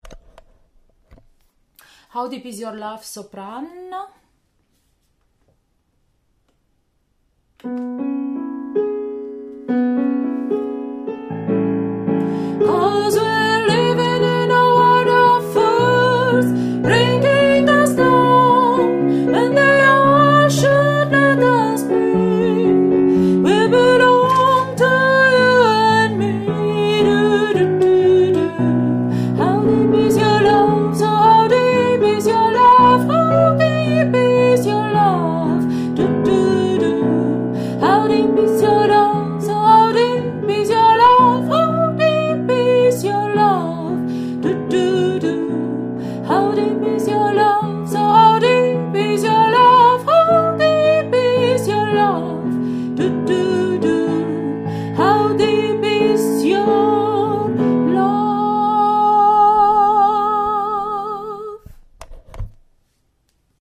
How deep is your love – Sopran-Schluss